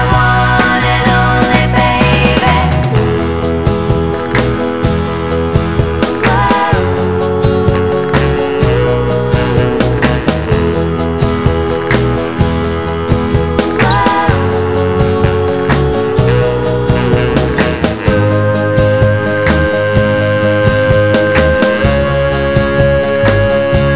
Two Semitones Down Christmas 2:46 Buy £1.50